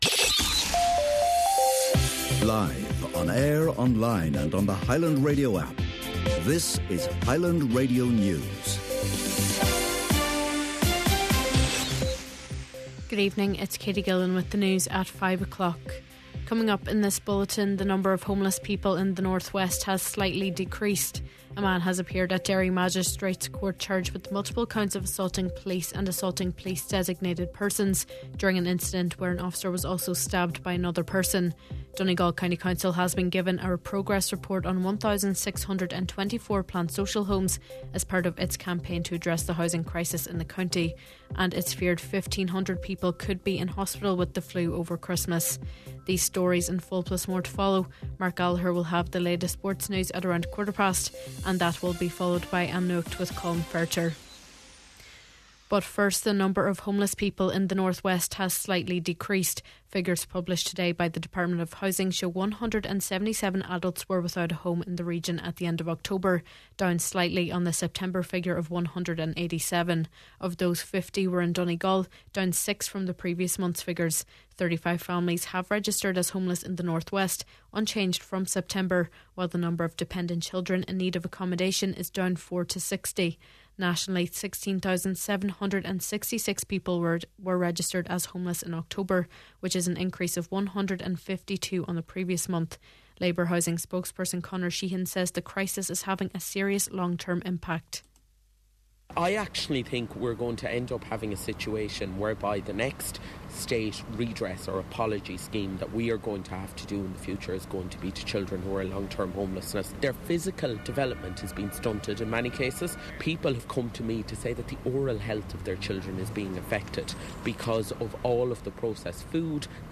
Main Evening News, Sport, an Nuacht and Obituary Notices – Friday November 28th